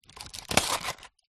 Звуки аптечки
Звук вскрытия аптечки